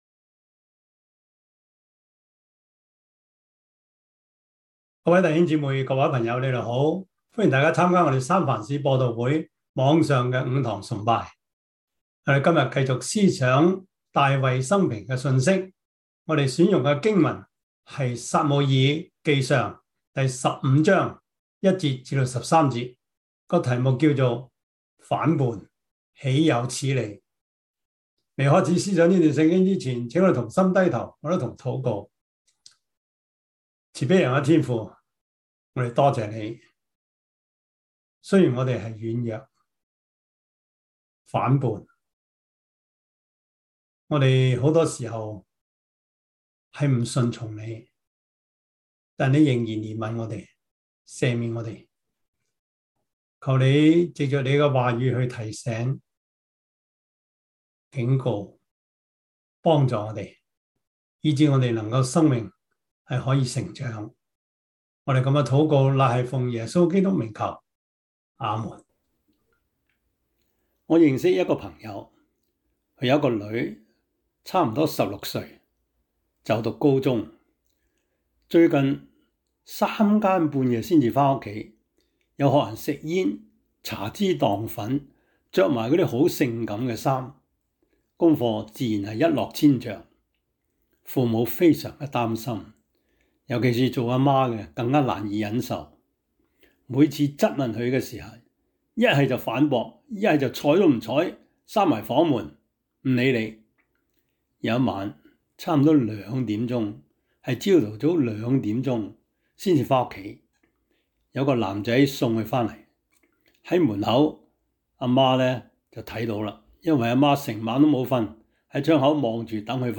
撒母耳記上 15:1-13 Service Type: 主日崇拜 撒母耳記上 15:1-13 Chinese Union Version